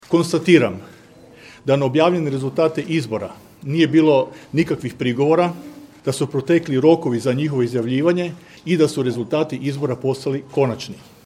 Novoj-staroj vlasti, županu Matiji Posavcu i zamjeniku Josipu Grivcu, rezultate je uručio predsjednik Županijskog izbornog povjerenstva Milko Sambolek: